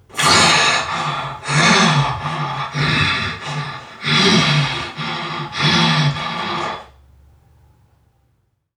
NPC_Creatures_Vocalisations_Robothead [69].wav